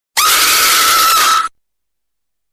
Scary Maze Game Scream sound effects free download
Scary Maze Game Scream - Meme Sound Effect